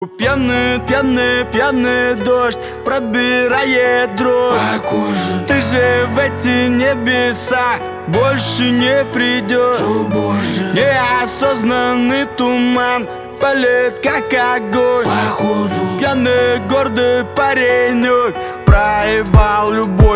• Качество: 320, Stereo
лирика
Хип-хоп
грустные
русский рэп